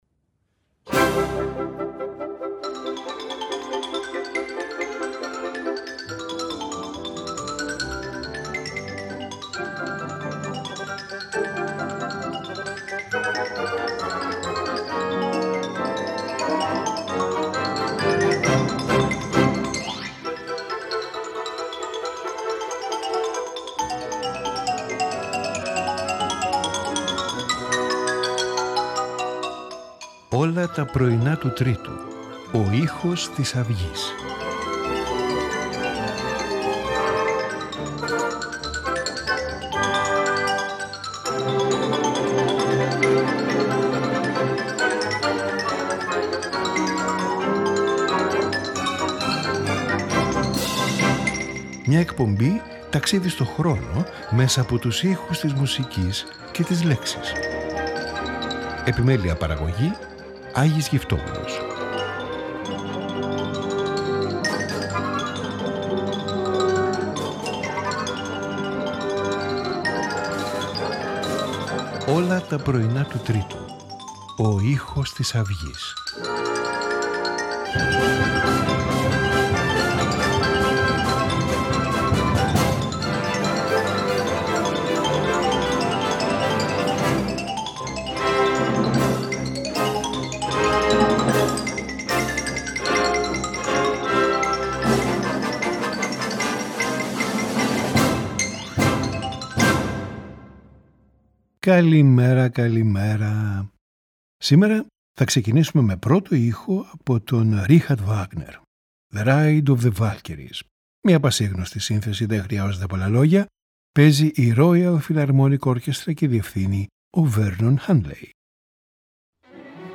Violin and Piano
Symphony
String Quartet
Three Harpsichords